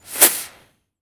rocketaim.wav